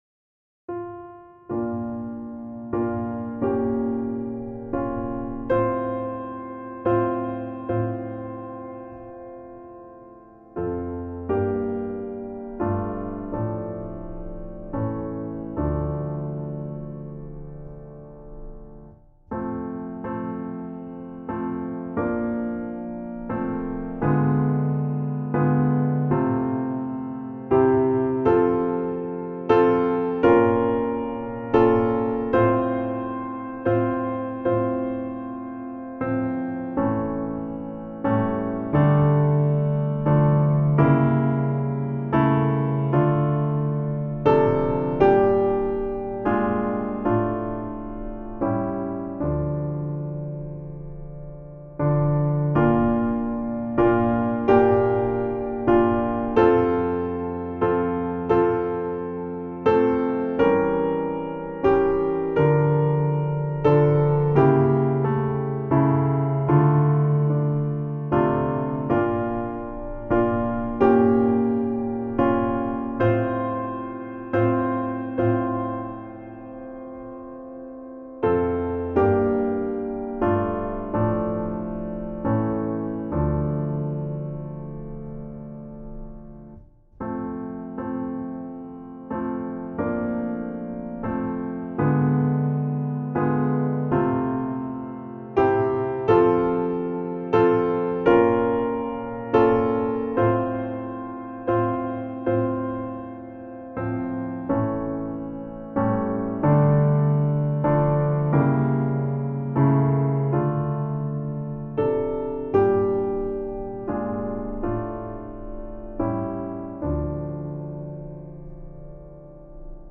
Voicing/Instrumentation: SATB , Choir Unison